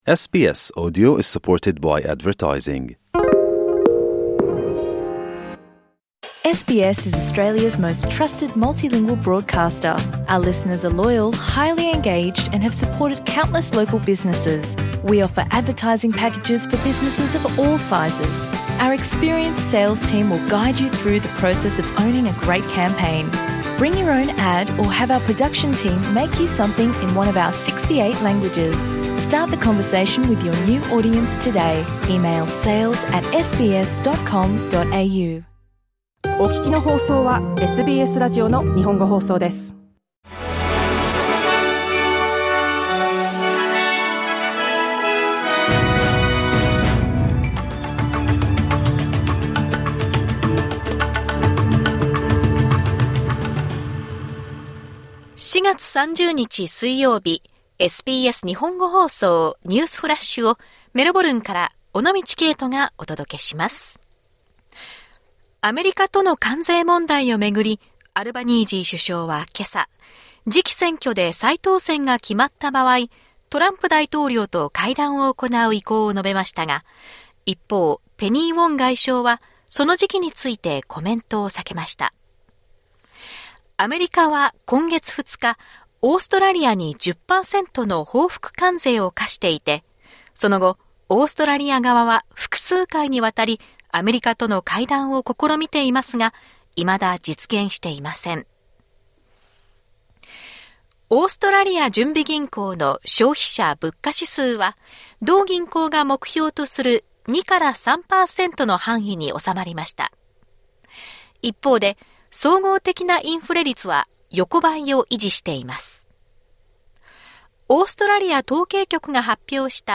SBS日本語放送ニュースフラッシュ 4月30日 水曜日